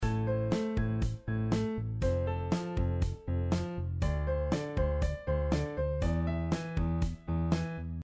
И пользуясь случаем прописал ударные и бас.